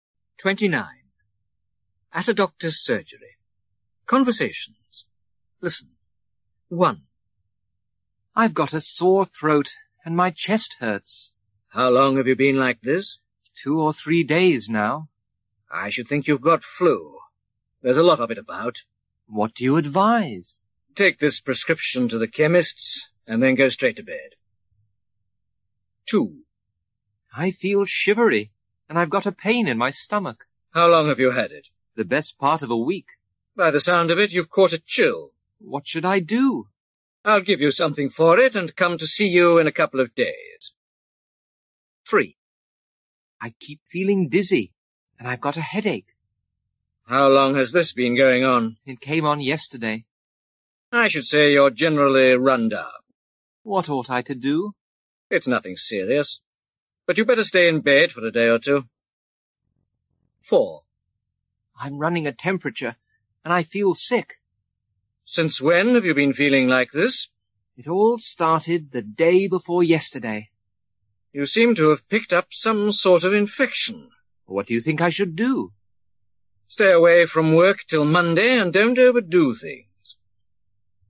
مجموعه مکالمات اجتماعی زبان انگلیسی – درس شماره بیست و هشتم: در مطب دکتر